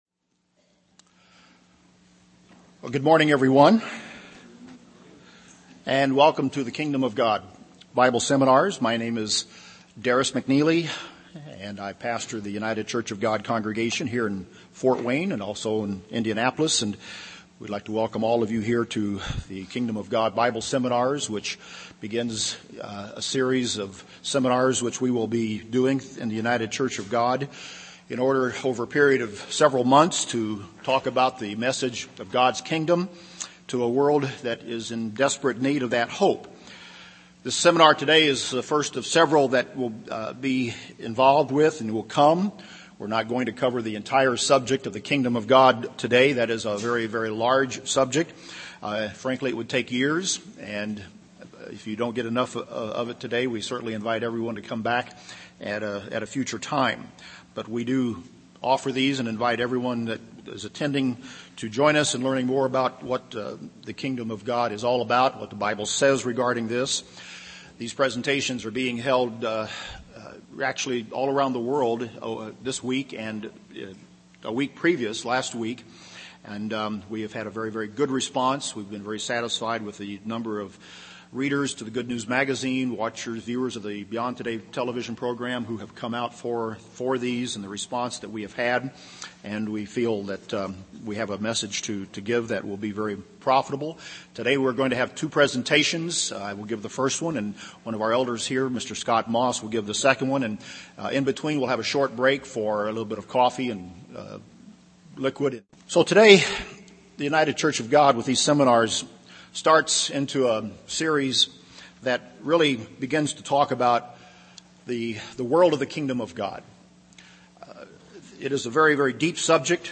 UCG Sermon Transcript This transcript was generated by AI and may contain errors.